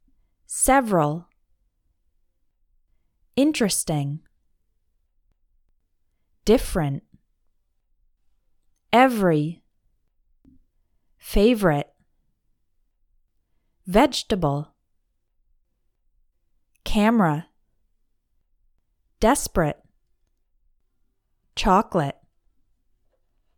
Words that native speakers usually pronounce “shorter”
There are certain words which you may be pronouncing very carefully, making sure to say each syllable clearly – but native English speakers will often “drop” one syllable in the word when speaking fast: